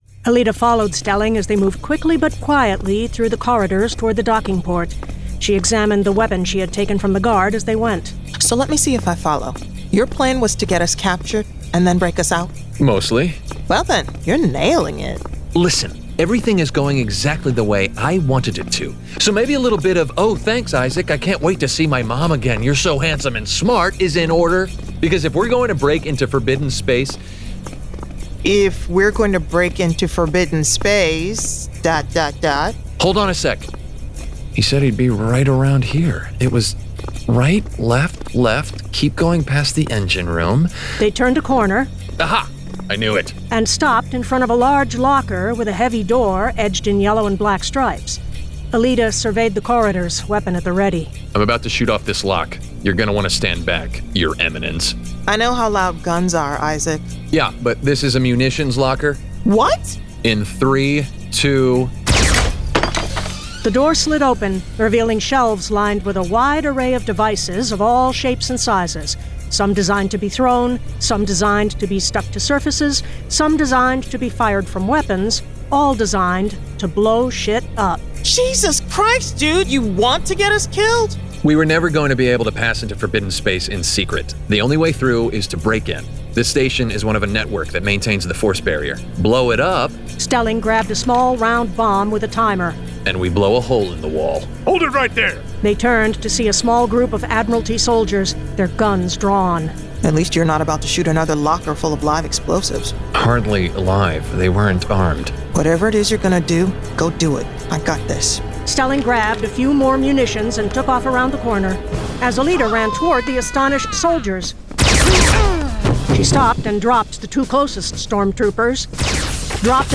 Full Cast. Cinematic Music. Sound Effects.
[Dramatized Adaptation]
Adapted directly from the graphic novel and produced with a full cast of actors, immersive sound effects and cinematic music.